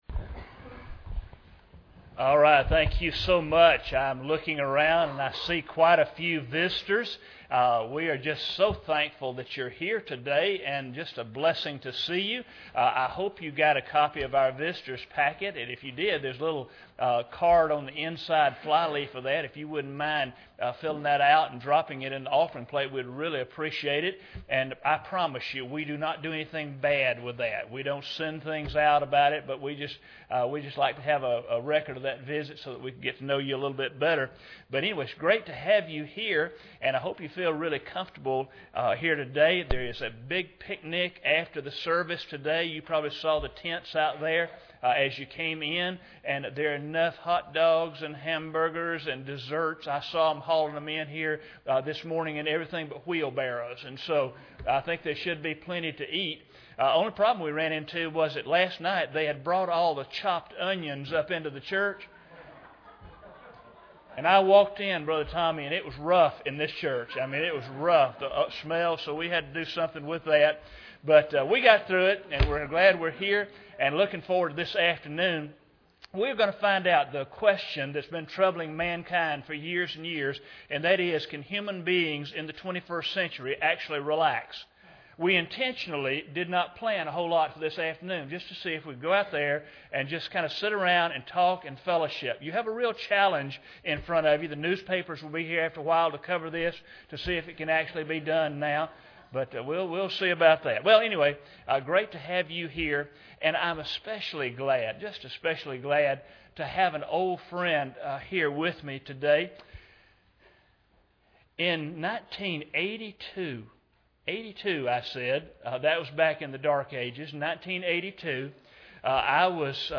1 Corinthians 1:18-25 Service Type: Sunday Morning Bible Text